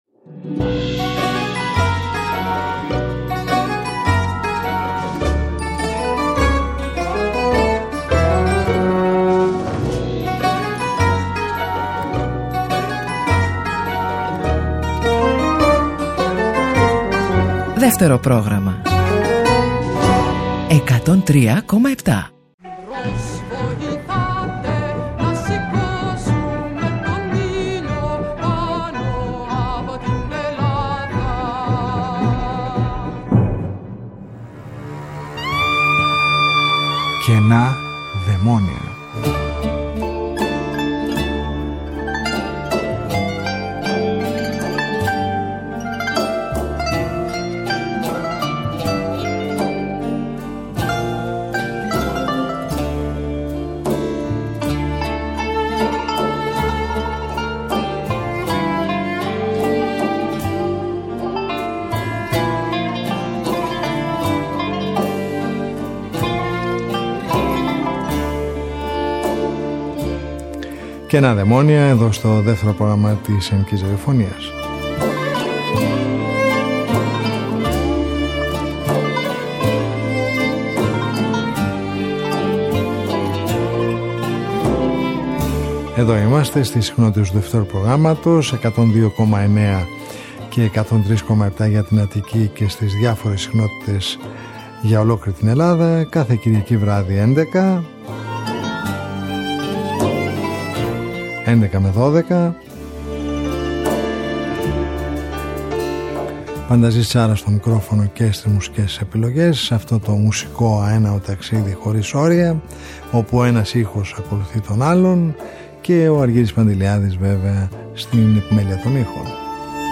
Μια ραδιοφωνική συνάντηση κάθε Κυριακή στις 23:00 που μας οδηγεί μέσα από τους ήχους της ελληνικής δισκογραφίας του χθες και του σήμερα σε ένα αέναο μουσικό ταξίδι.